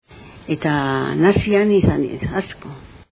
Dialectos
Salacenco